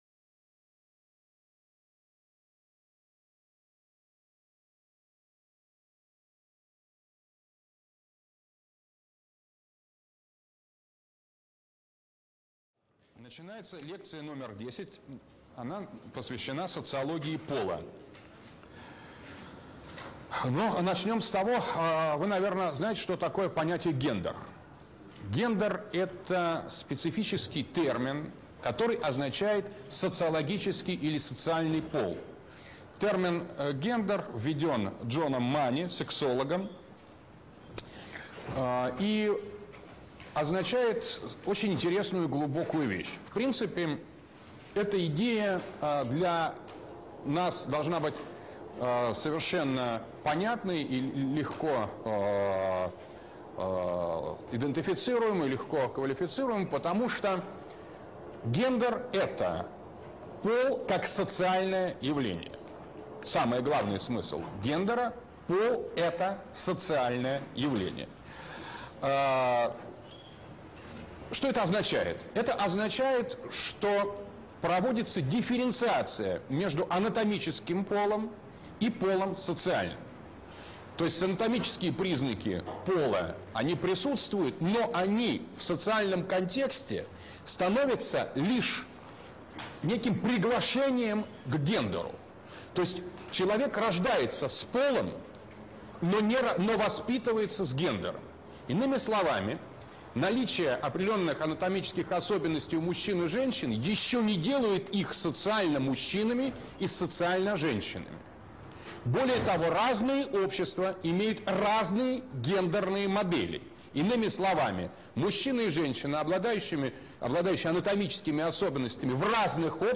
Структурная социология. Лекция 2. Социология и антропологические структуры воображаемого
Читает А.Г. Дугин. Москва, МГУ.